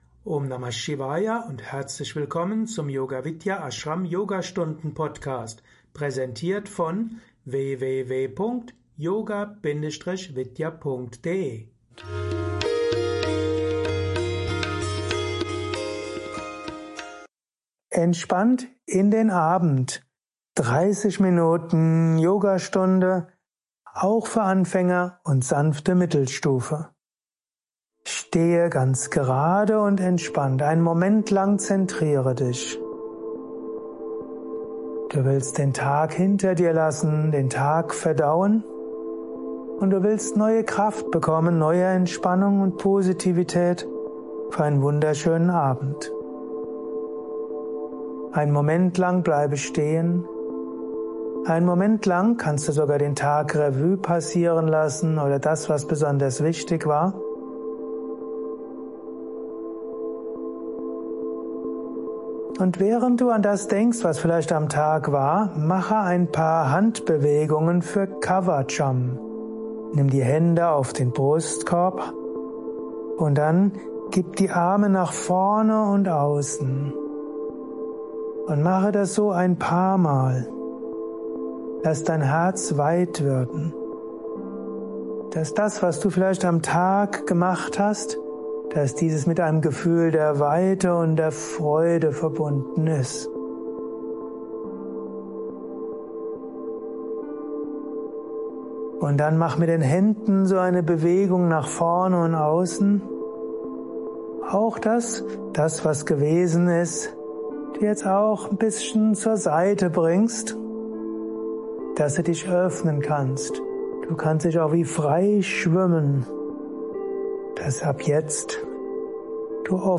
Special Yogastunden Podcast
Ruhig_und_gemuetlich_30_Min_Yoga_am_Abend.mp3